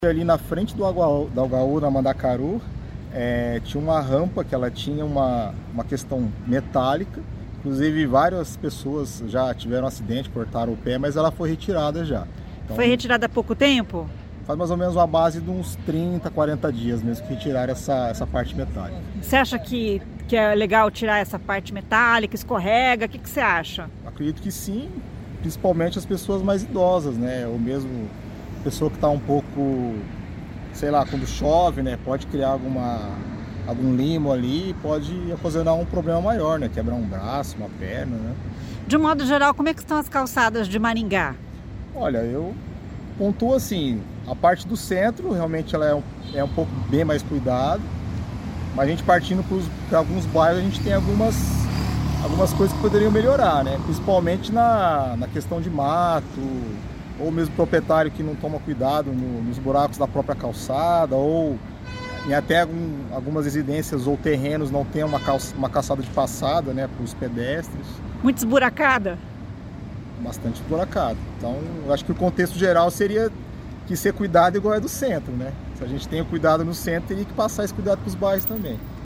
E a reportagem da CBN foi às ruas para saber a opinião das pessoas sobre a rampa metálica e sobre a conservação de calçadas de um modo geral.